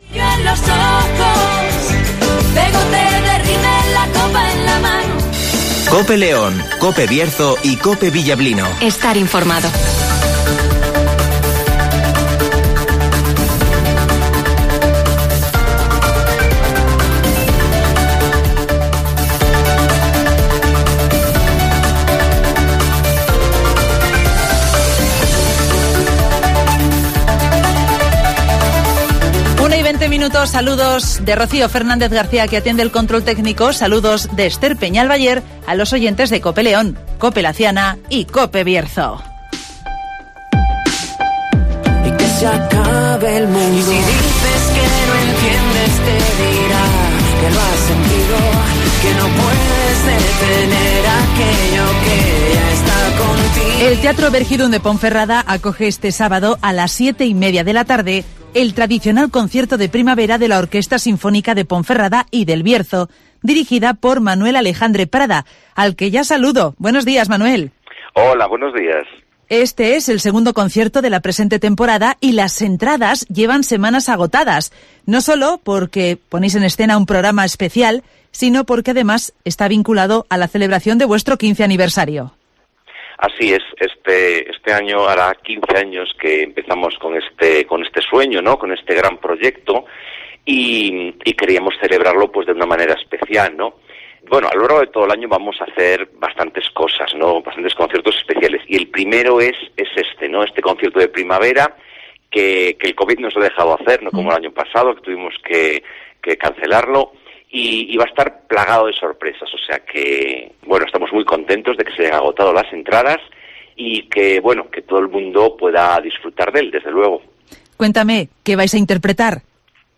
La Orquesta Sinfónica de Ponferrada y del Bierzo ofrecerá el sábado, 17 de abril el Concierto de Primavera (Entrevista